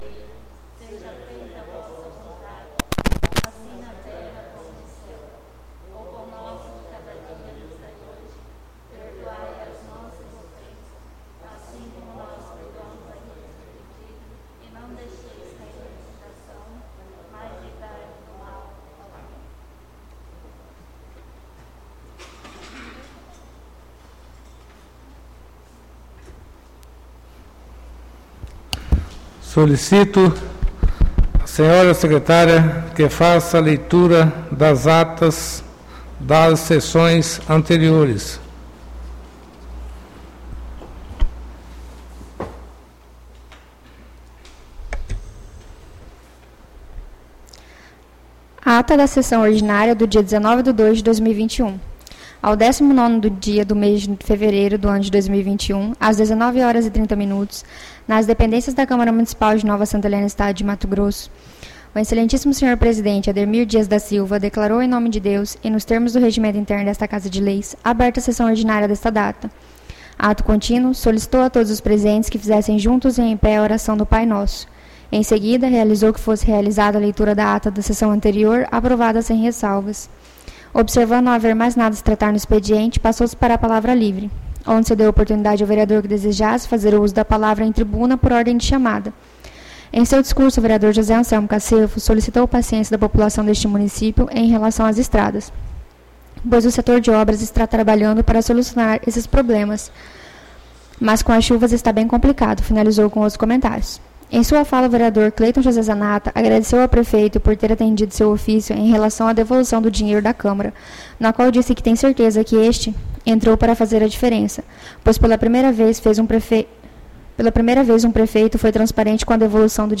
ÁUDIO SESSÃO 09-03-21 — CÂMARA MUNICIPAL DE NOVA SANTA HELENA - MT